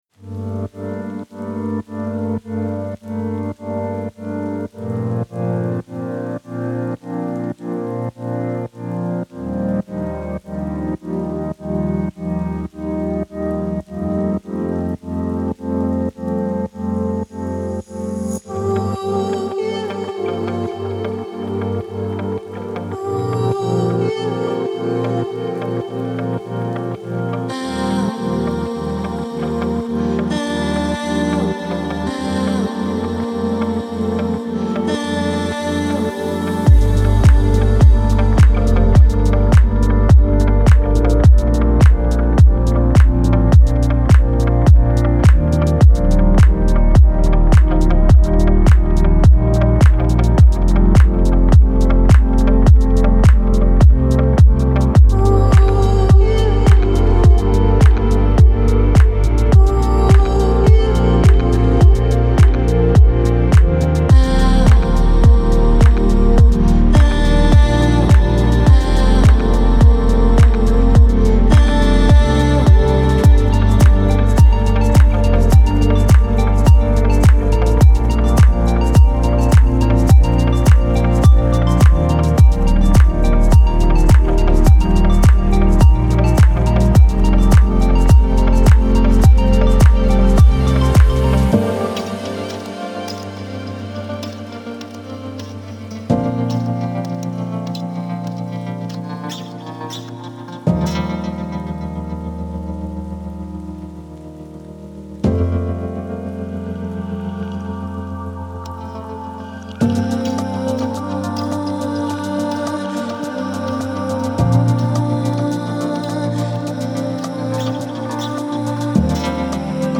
موسیقی کنار تو
آرامش بخش , عمیق و تامل برانگیز , موسیقی بی کلام